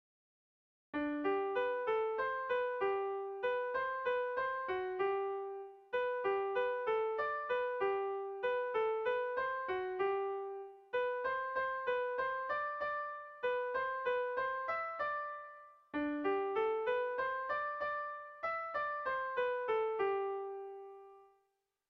Irrizkoa
A1A2BD